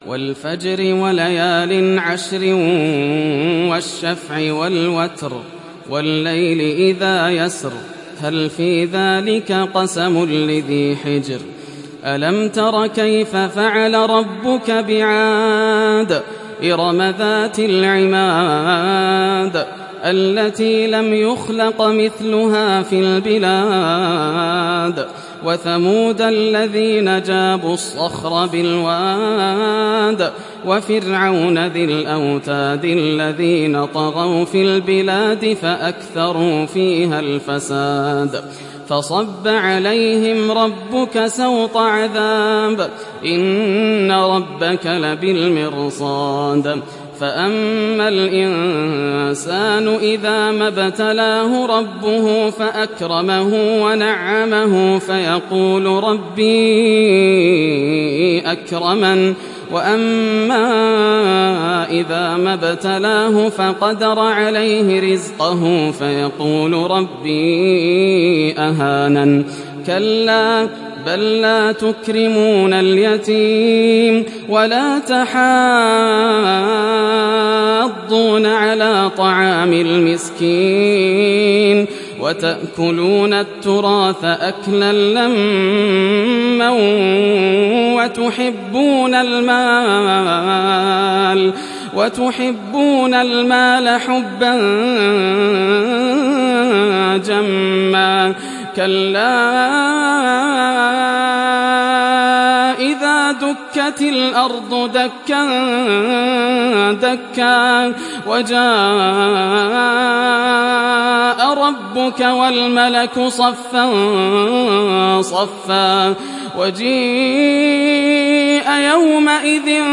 دانلود سوره الفجر mp3 ياسر الدوسري (روایت حفص)